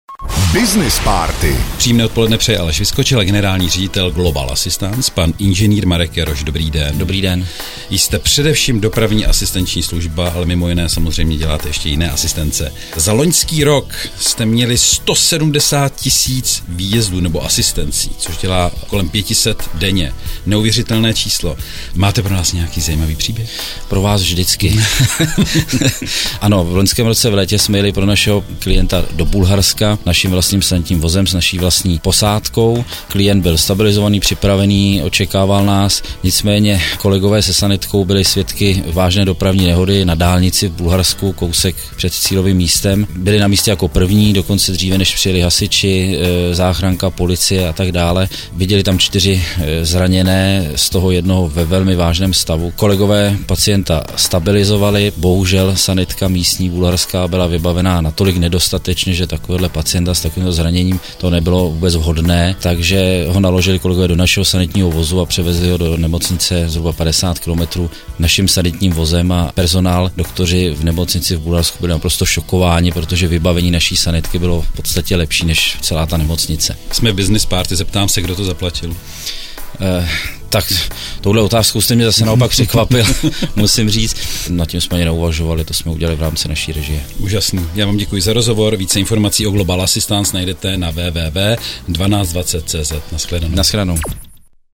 Business párty 2018 – FREKVENCE 1